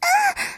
moan8.ogg